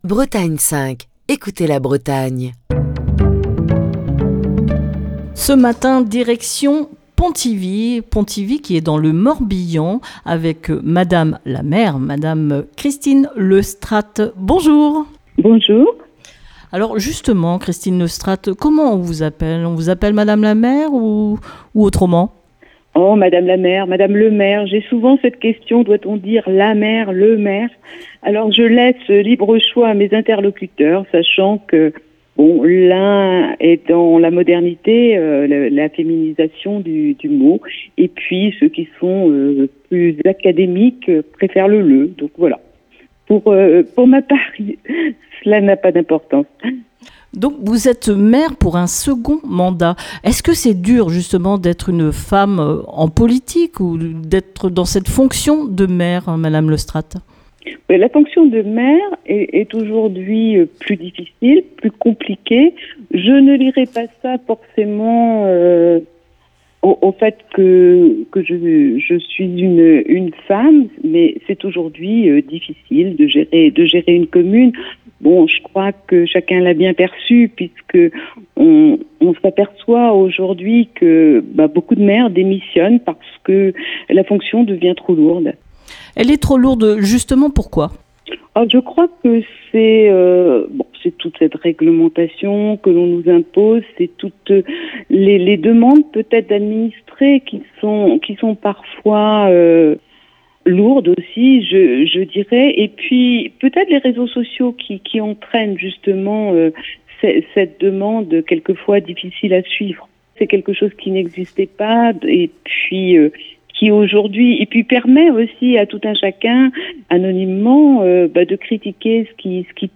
au téléphone avec Christine Le Strat, la maire de Pontivy, dans le Morbihan, où Destination Commune fait escale cette semaine.